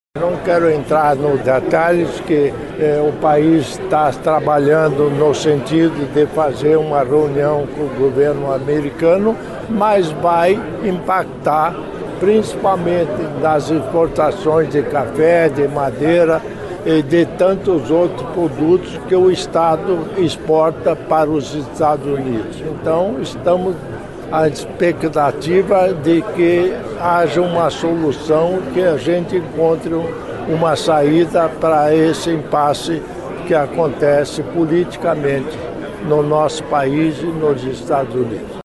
A taxação de 50% anunciada pelo governo americano aos produtos exportados pelo Brasil ainda não entrou em vigor, mas já preocupa bastante os setores agrícola e industrial do estado. As novas tarifas vão impactar as exportações de café, madeira, entre outros produtos que saem do Paraná com destino aos Estados Unidos, como destacou o governador em exercício, Darci Piana.